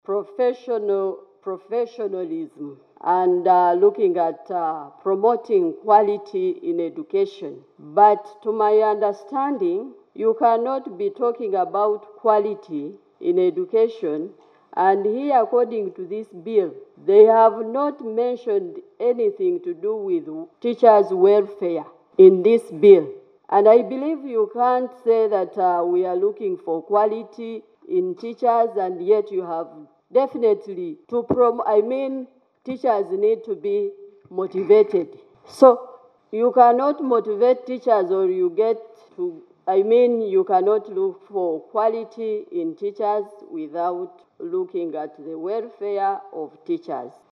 The legislators spoke in Parliament's Committee on Education, which hosted private schools’ teachers for their views on the Bill, on Thursday 03 October, 2024.